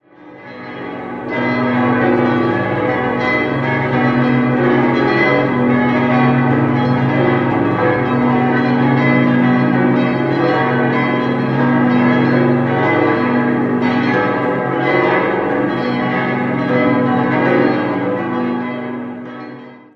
10-stimmiges Geläute: h°-c'-cis'-dis'-fis'-gis'-h'-dis''-fis''-gis''
Unverwechelbares Geläute, dem insbesondere die Kunigundenglocke mit ihrem für Bienenkorbglocken typischen herb-eigentümlichen Klang eine besondere Note verleiht.
Bamberg_Dom.mp3